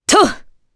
Priscilla-Vox_Attack3_jp.wav